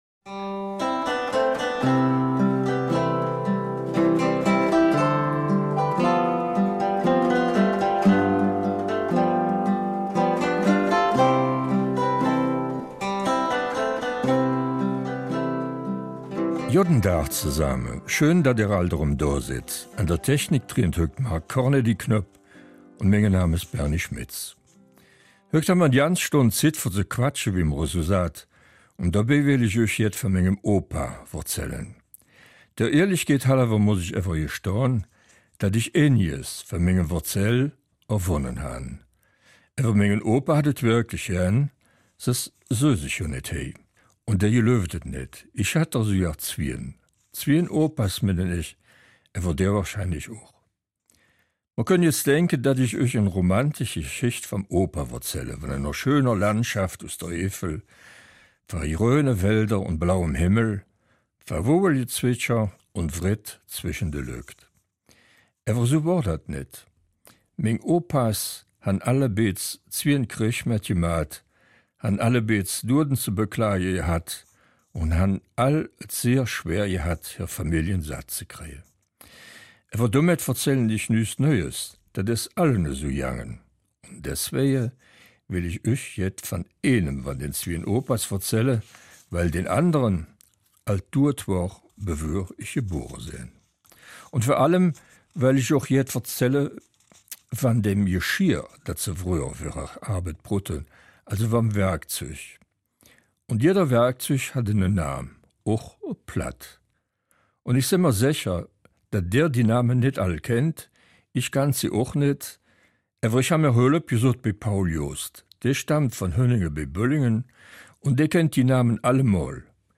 Eifeler Mundart - 29.